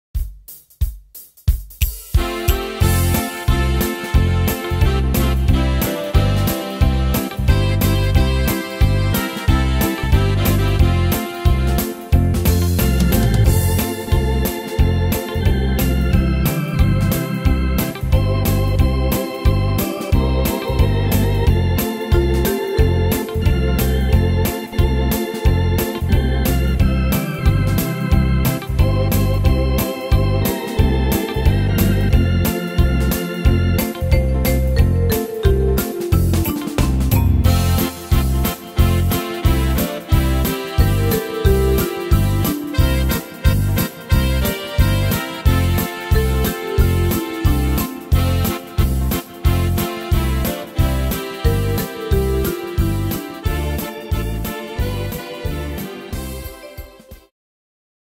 Tempo: 180 / Tonart: F-Dur